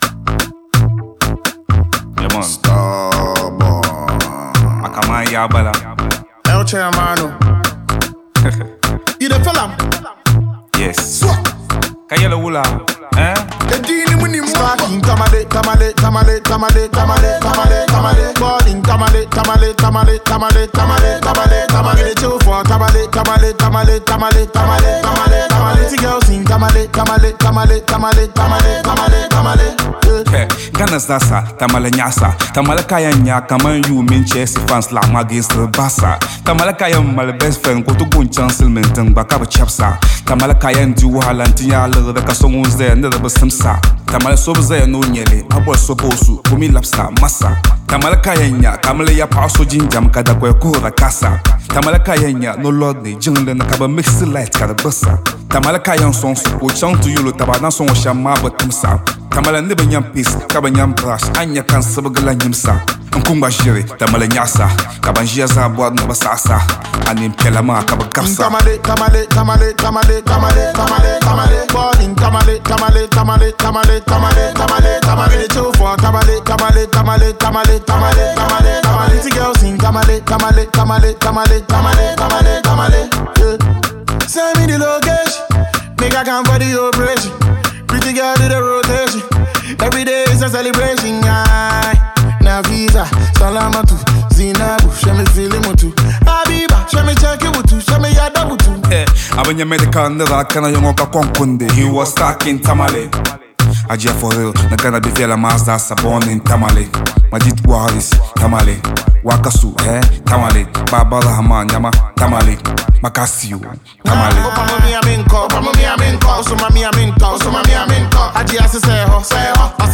New banger from Ghanaian rapper